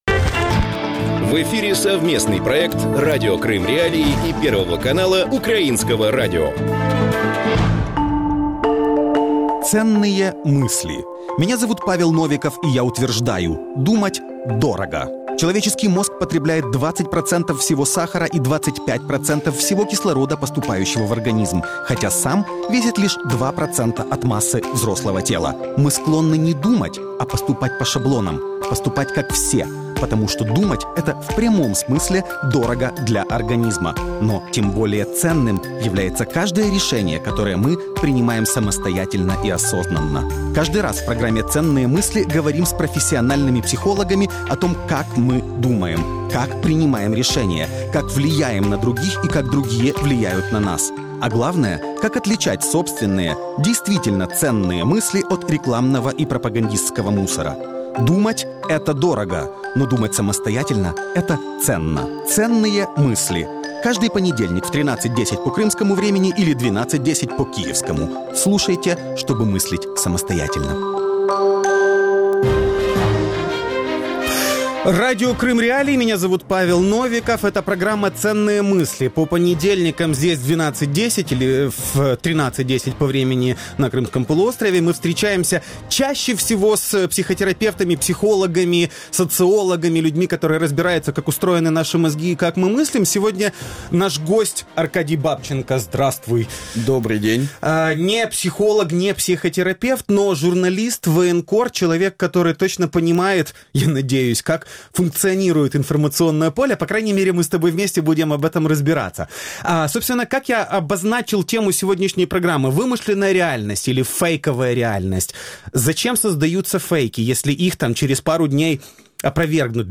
Гость студии – российский писатель и журналист, военный корреспондент Аркадий Бабченко.